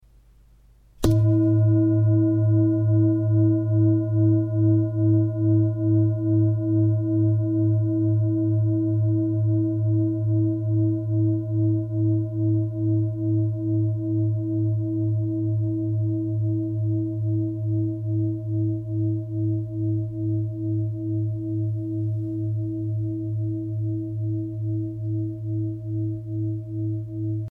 Indische Klangschale  BECKENSCHALE  2579g KMF3R
Gewicht: 2579 g
Durchmesser: 28,7 cm
Grundton: 107,95 Hz
1. Oberton: 323,08 Hz